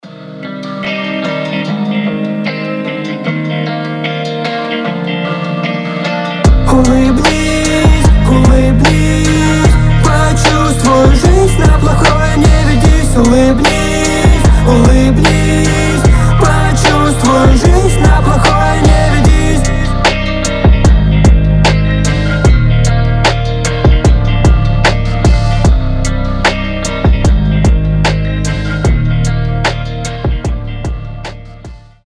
• Качество: 320, Stereo
гитара
позитивные
мелодичные
добрые